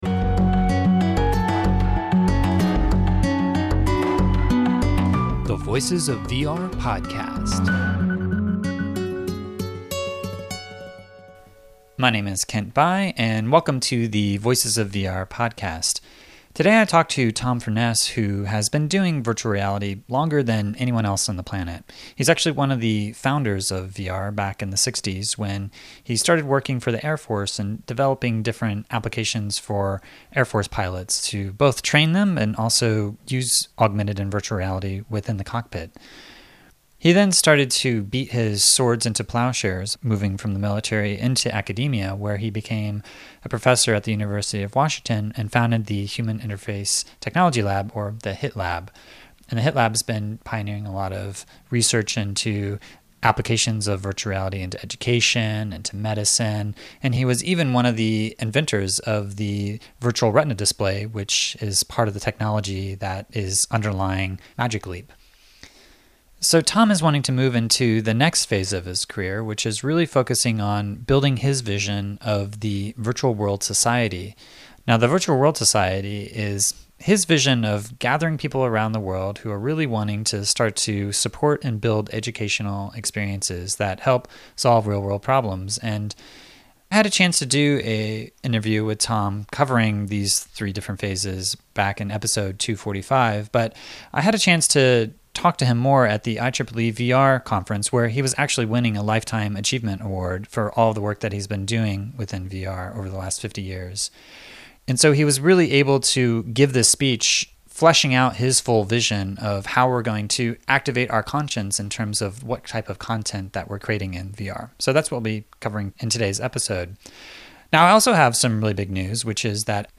But I was able to catch up with him again at the IEEE VR conference just after he had just won a Lifetime Achievement Award awarded through the IEEE VGTC publication, which publishes the IEEE VR conference proceedings.